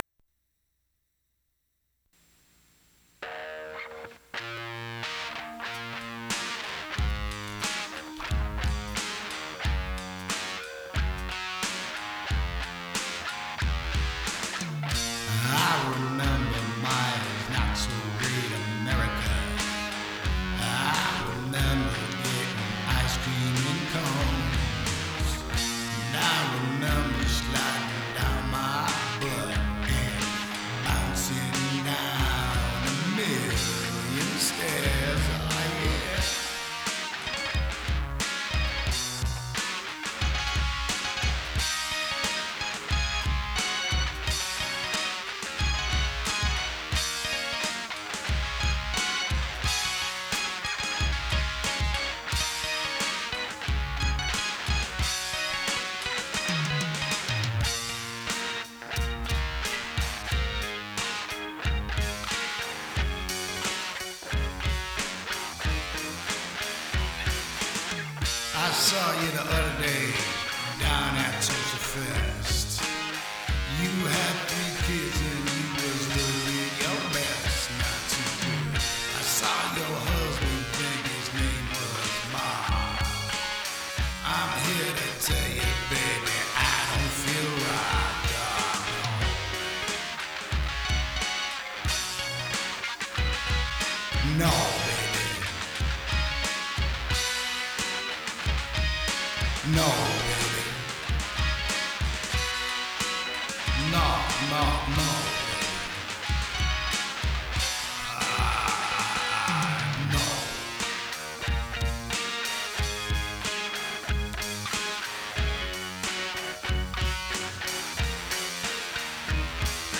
recorded live 10/2016